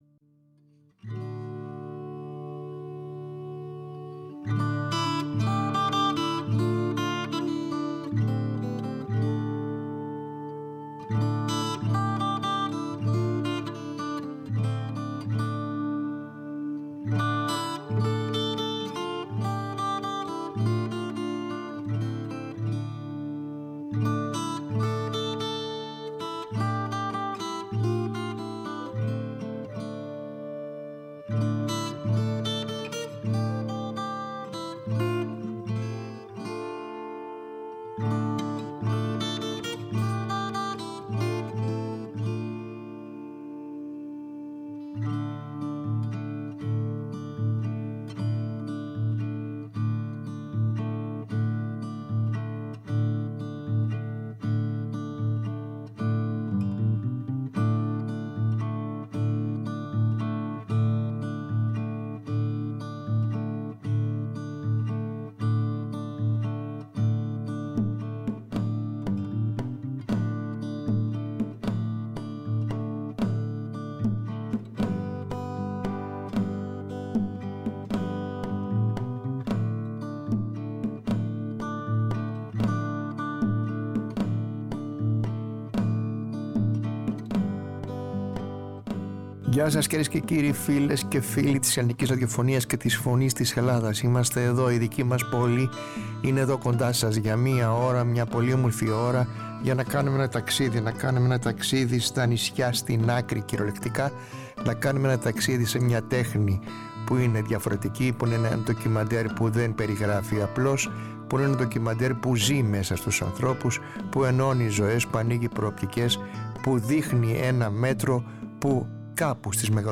Στο στούντιο